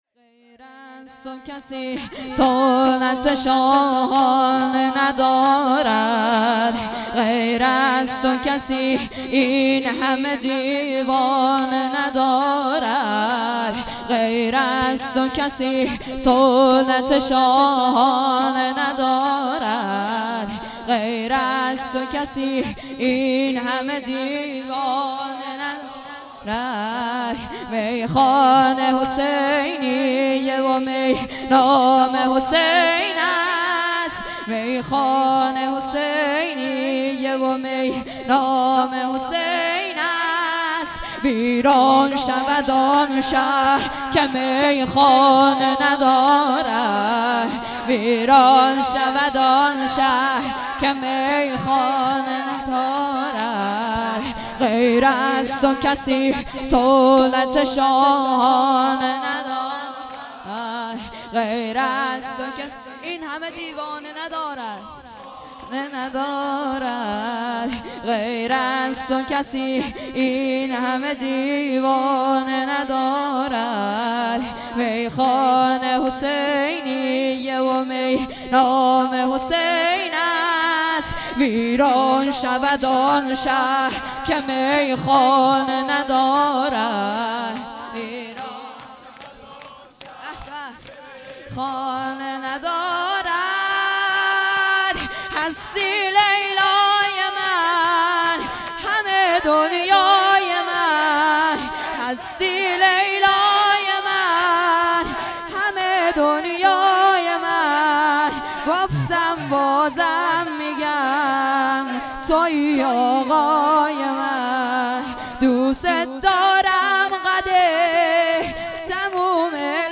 هیئت دانش آموزی انصارالمهدی
مداحی